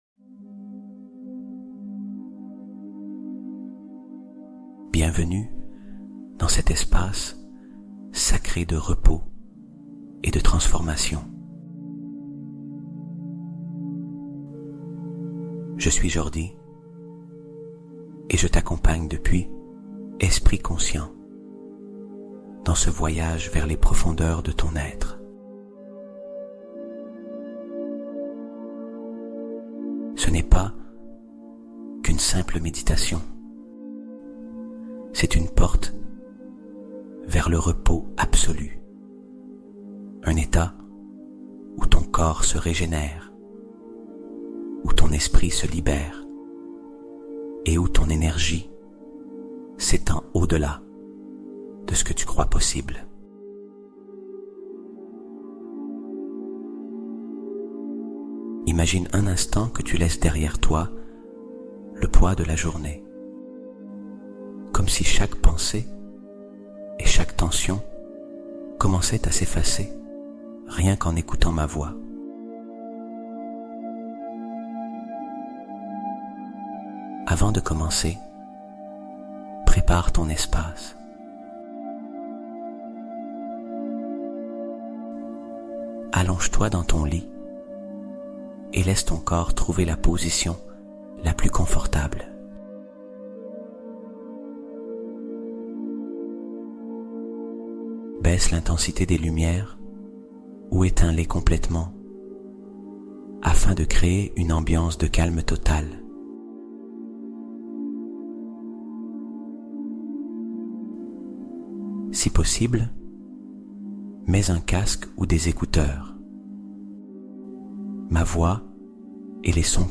Sommeil Instantané : Hypnose profonde pour s'endormir en 3 minutes